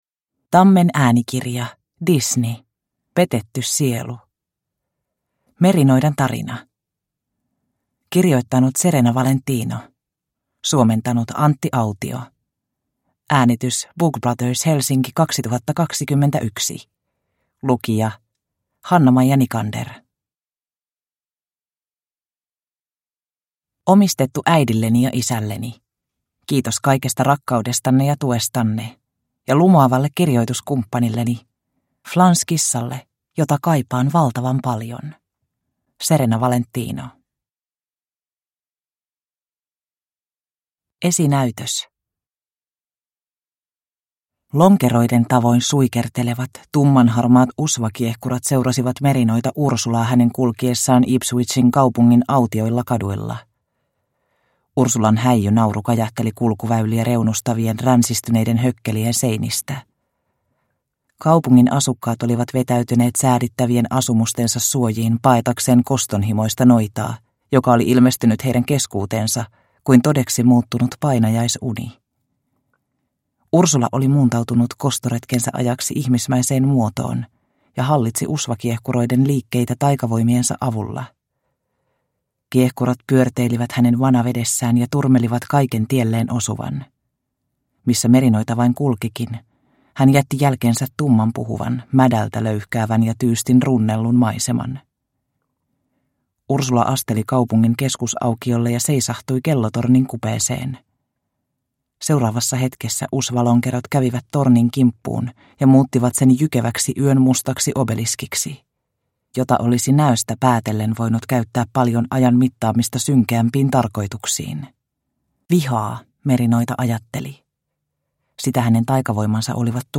Petetty sielu – Ljudbok – Laddas ner